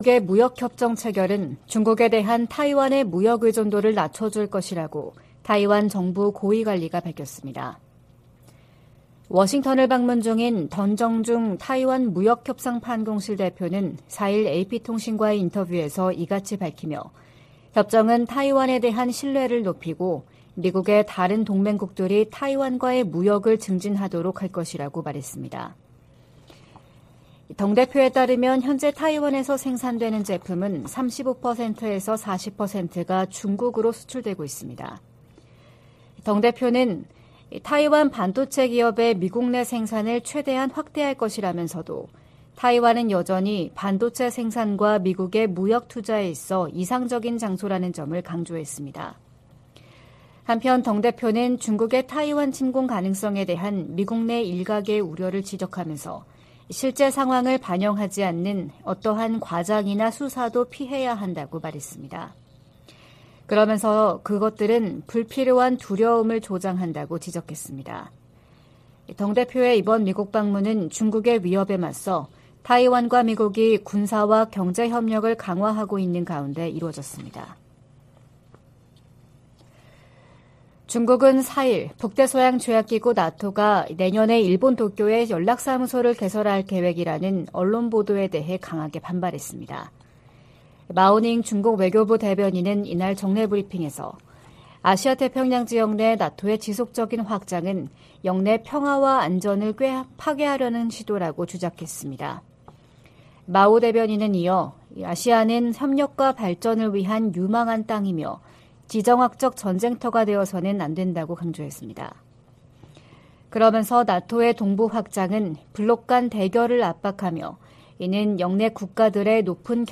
VOA 한국어 '출발 뉴스 쇼', 2023년 5월 5일 방송입니다. 한일·미한일 정상회담이 이달 중 연이어 개최 될 예정입니다. 미 국무부가 기시다 후미오 일본 총리의 한국 방문 계획을 환영했습니다.